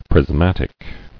[pris·mat·ic]